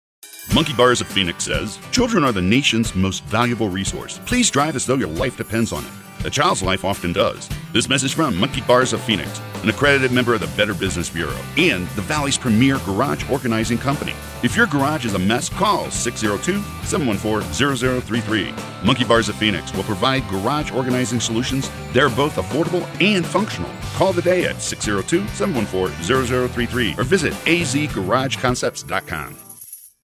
Listen to a recent radio commercial.